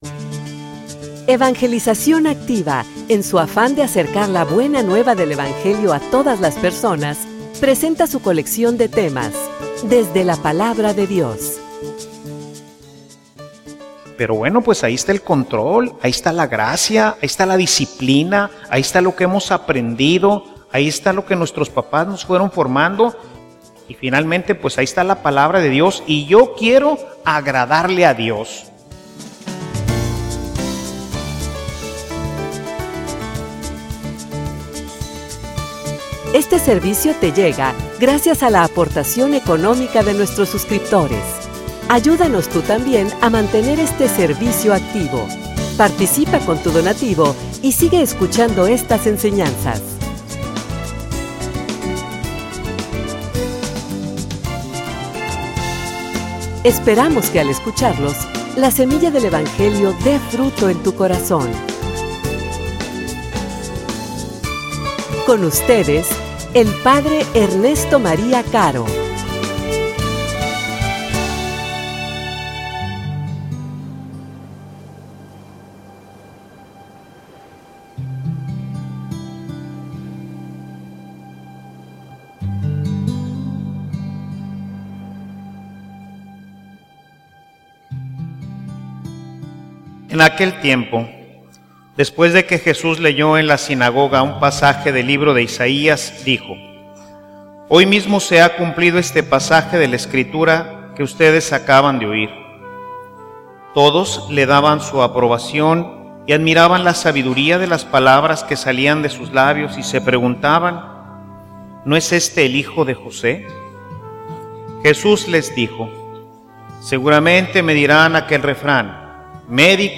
homilia_Una_predicacion_incomoda.mp3